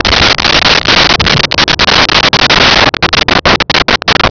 Sfx Thunder 07
sfx_thunder_07.wav